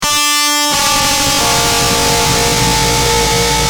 Screamb3.wav